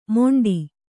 ♪ moṇḍi